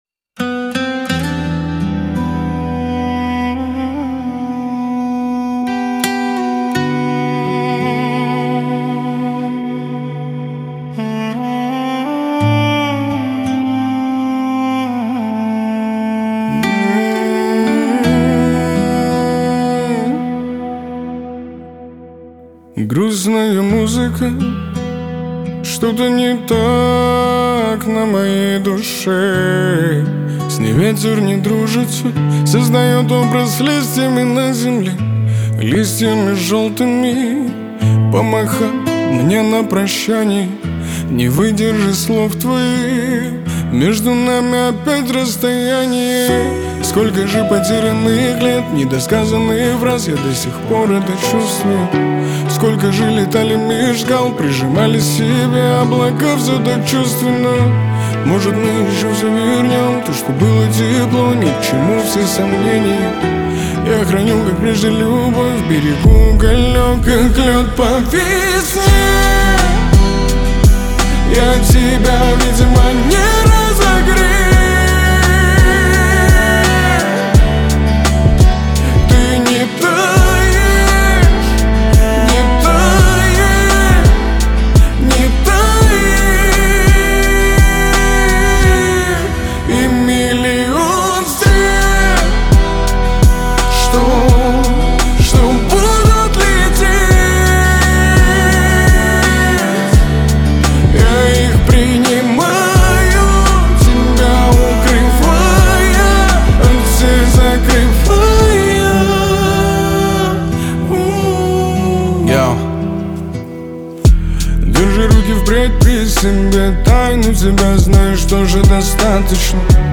Грустная музыка